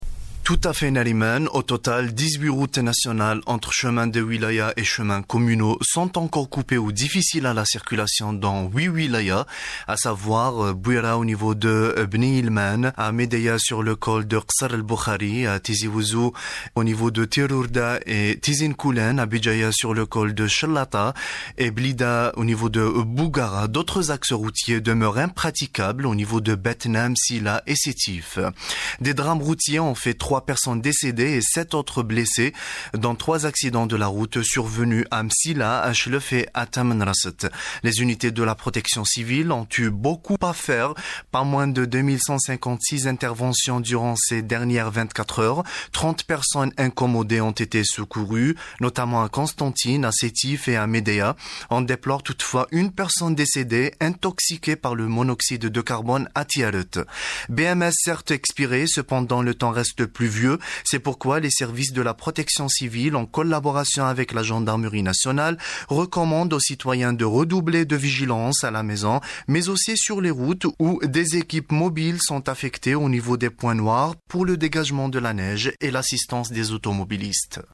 Etat des lieux à Sidi Belabbès - Correspondance